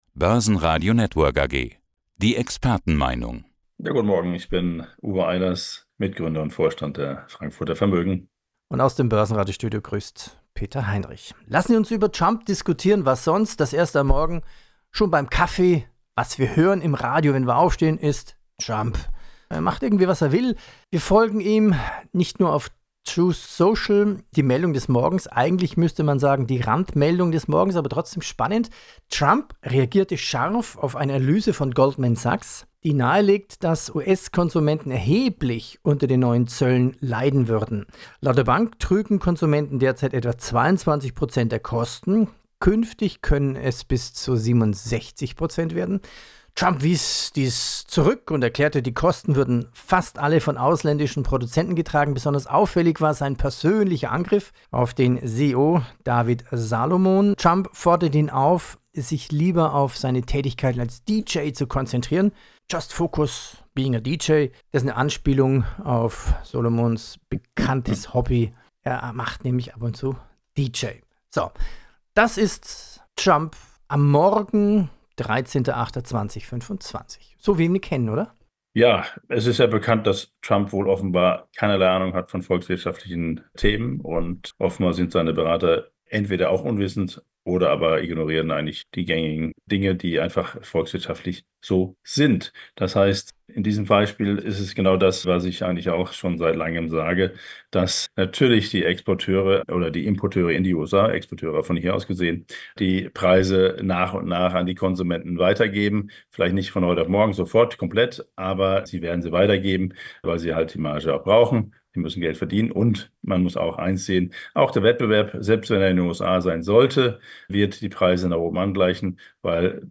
Radio report: ‘Indirect expropriation of foreign investors’ – US bonds going down the drain?